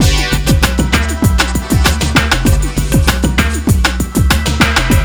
RAGGALOOP3-L.wav